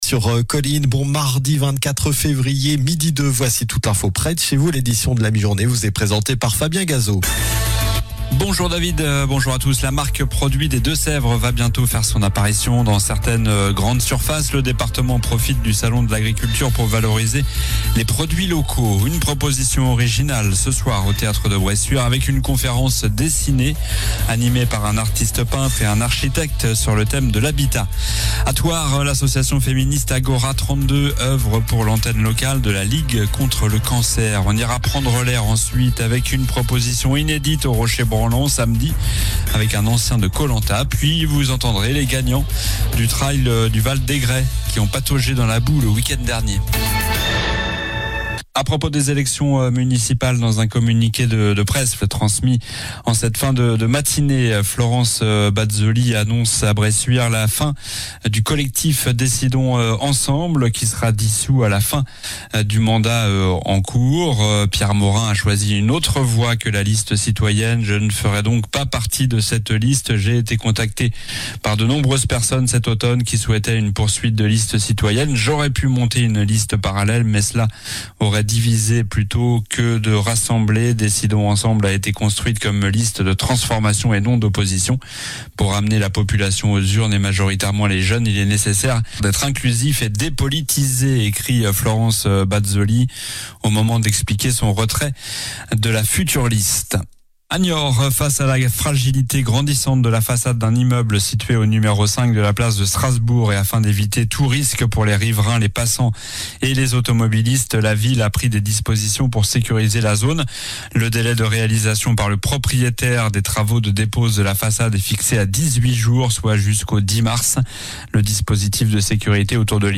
Journal du mardi 24 février (midi)